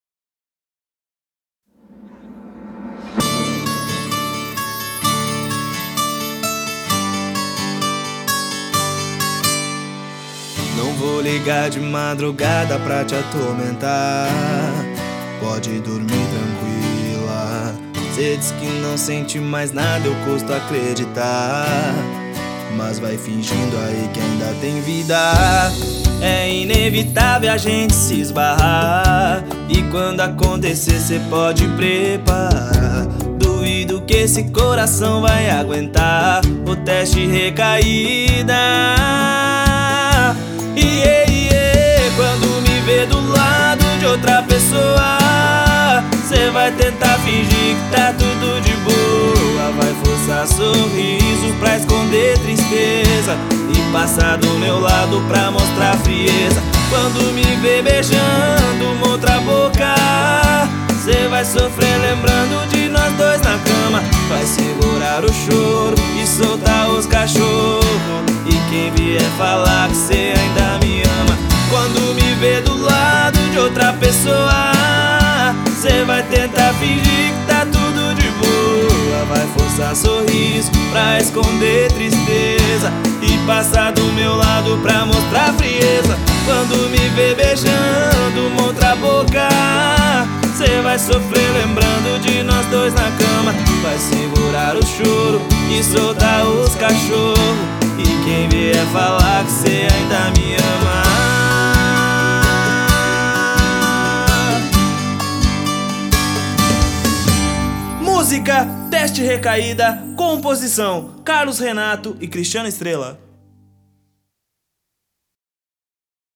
Sertanejo Romântico